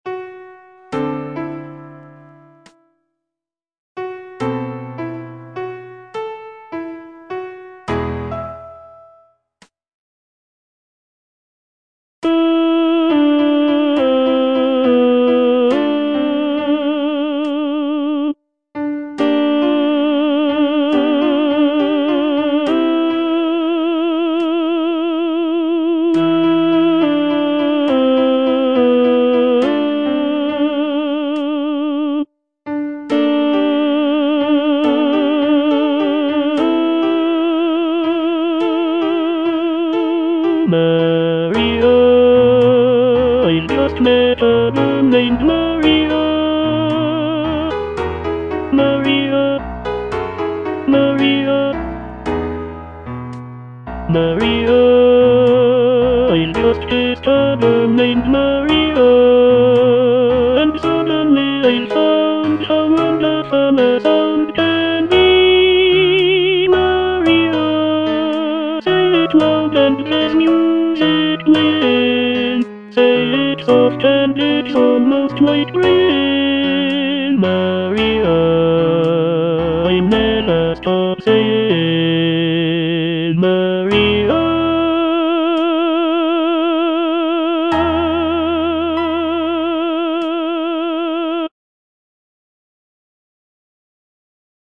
(tenor I) (Voice with metronome) Ads stop